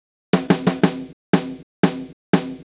Drumset Fill 01.wav